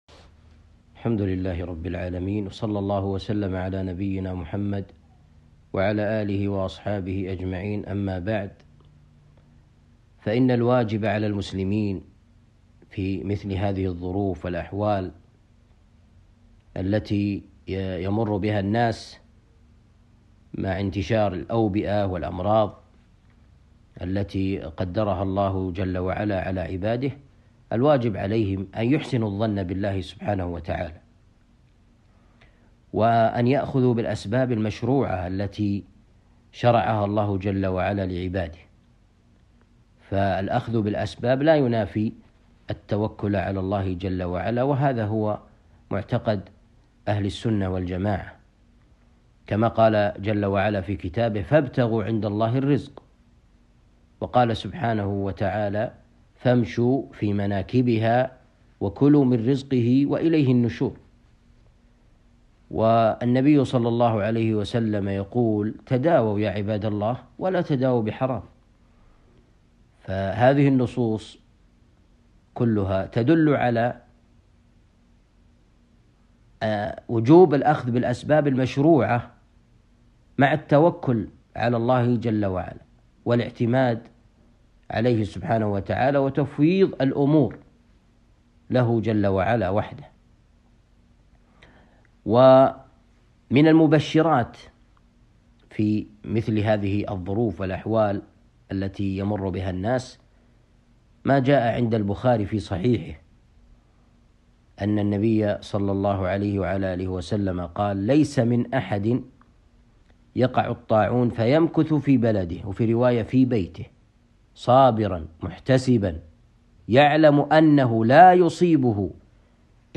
كلمة قصيرة وصايا للمسلمين في زمن الأوبئة والأمراض